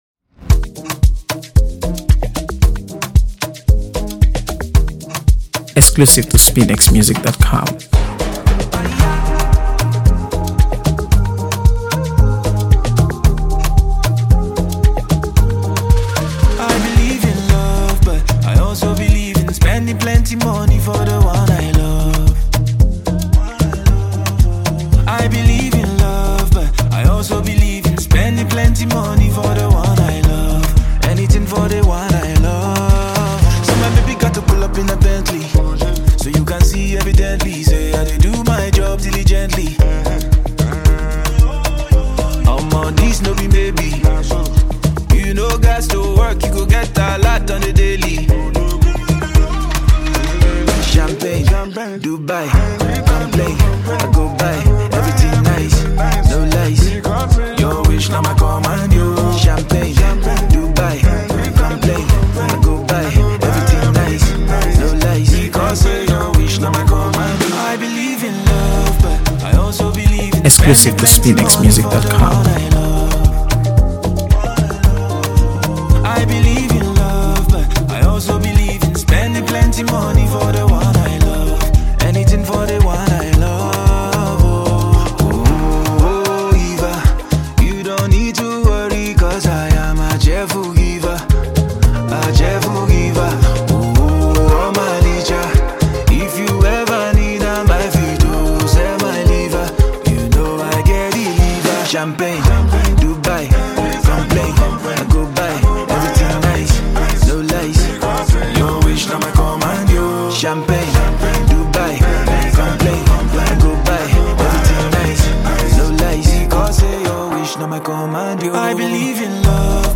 heartfelt lyrics and soothing melodies